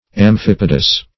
Amphipodous \Am*phip"o*dous\, a.
amphipodous.mp3